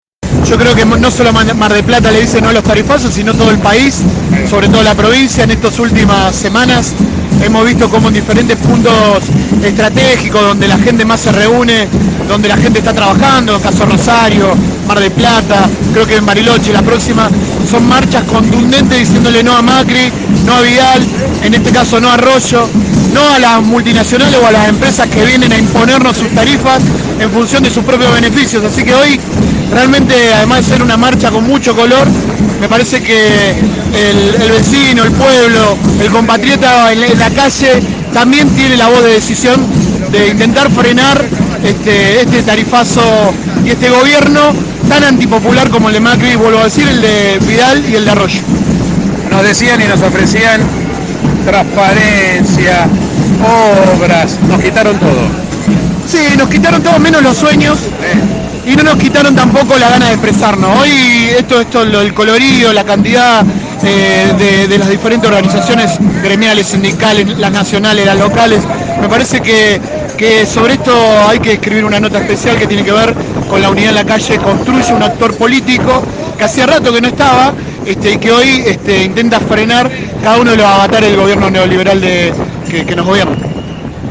En el programa radial Bien Despiertos, producido de lunes a viernes de 7:00 a 9:00 por De La Azotea 88.7, se recolectó varias voces de dirigentes que le dijeron “no a los tarifazos”, escúchalos.
Marcos Gutierrez, concejal de Unidad Ciudadana MdP/Batán: